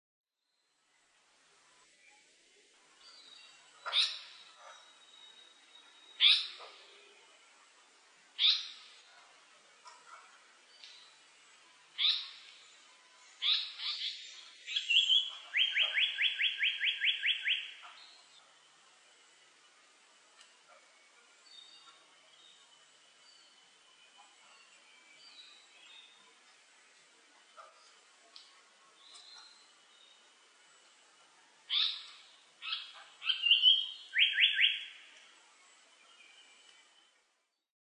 サンコウチョウ　Terpsiphone atrocaudataカササギヒタキ科
日光市稲荷川中流　alt=730m  HiFi --------------
MPEG Audio Layer3 FILE  Rec.: SONY TCD-D3
Mic.: audio-technica AT822
他の自然音：　 キビタキ